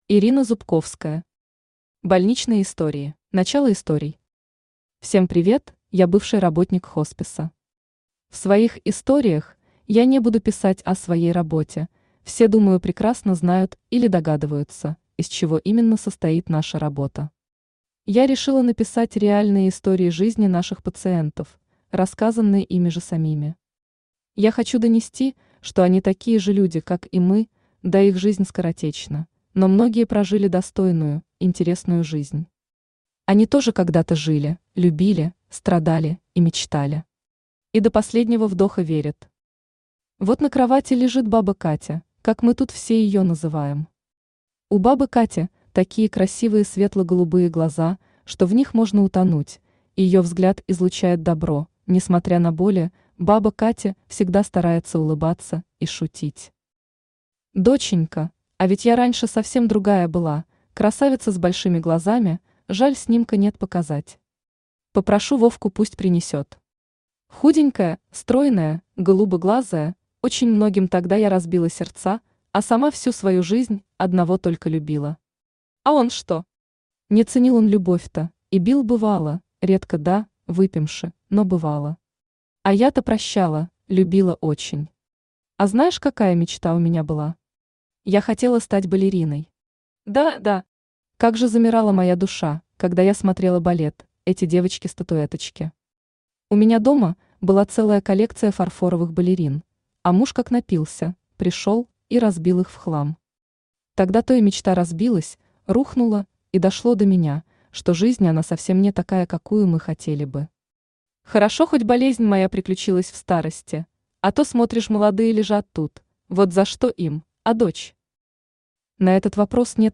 Аудиокнига Больничные истории | Библиотека аудиокниг
Aудиокнига Больничные истории Автор Ирина Зубковская Читает аудиокнигу Авточтец ЛитРес.